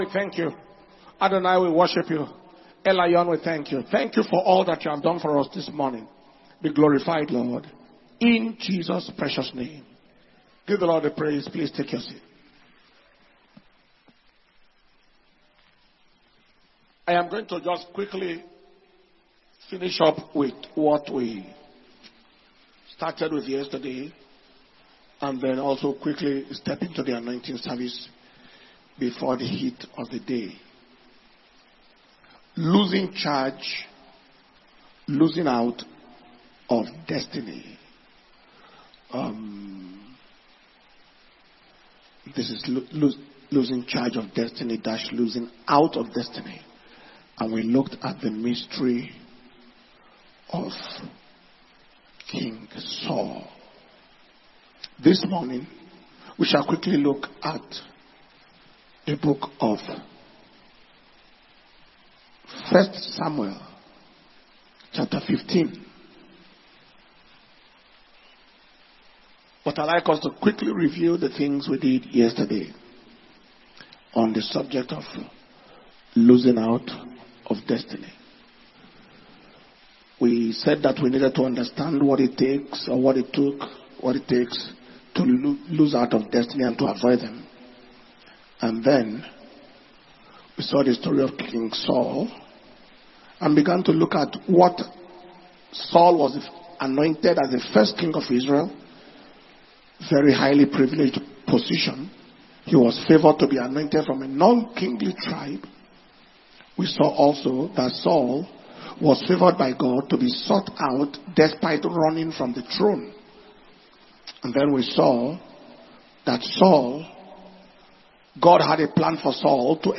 Day 4 Morning Session
Dunamis Destiny Recovery Convention May 2025 Messages